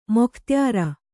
♪ mokhtyāra